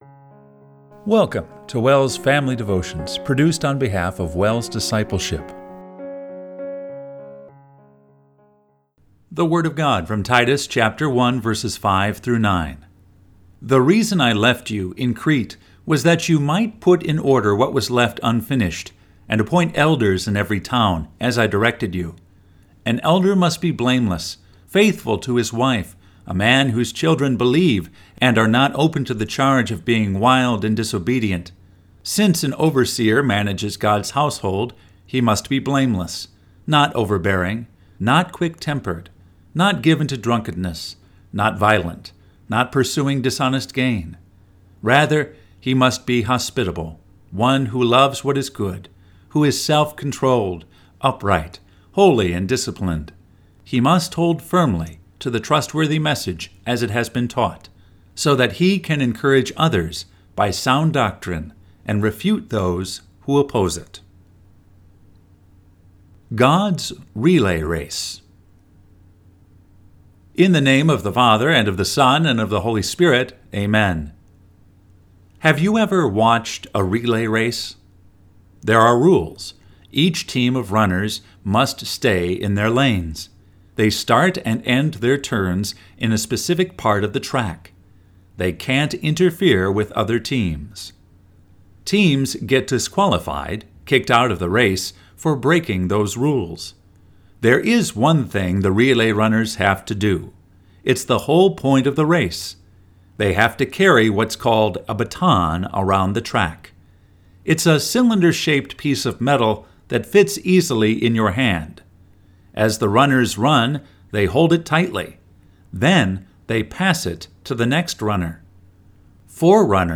Devotion based on Titus 1:5-9